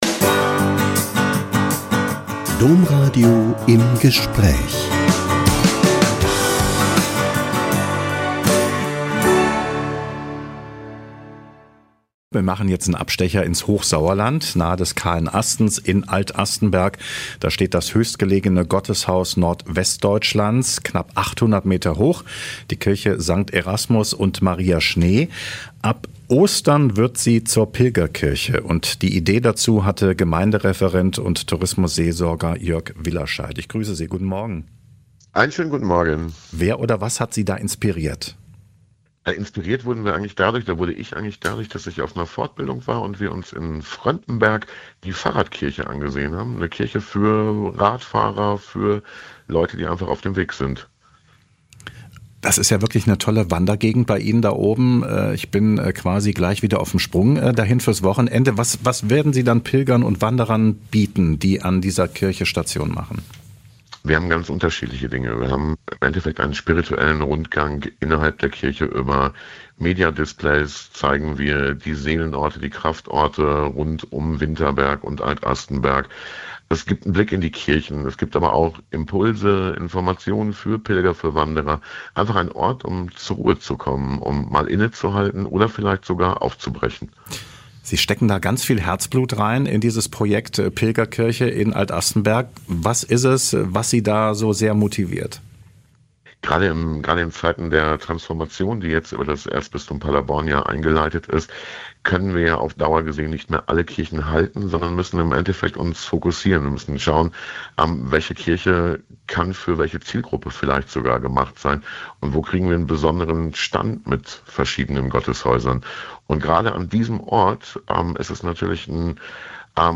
Interview über die höchste Pilgerkirche Nordwestdeutschlands